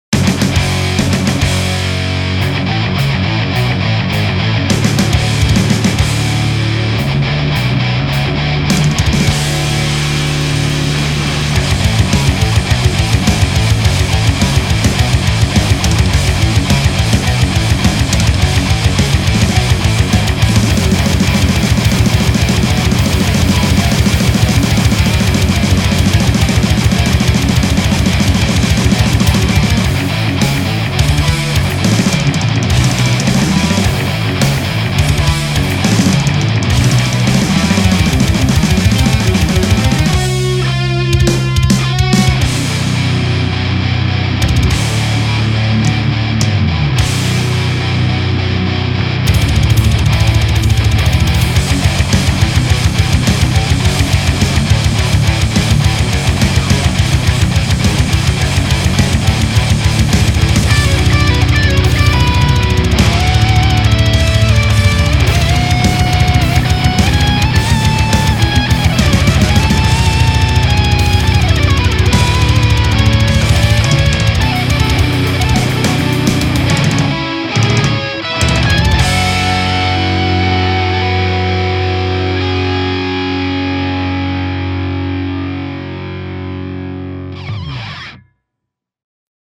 C'est un truc que j'ai composé rapidement, je voulais faire un truc plutot simple et efficace (il y aura aussi des passages clean mais d'abord je voulais faire les passages disto ).
Ca sonne très bien.
J'ai repris mon ampli avec deux sm 57 (l'un est une copie chinoise), et j'ai fais 4 prises dont deux de chaque coté sont compressé à fond.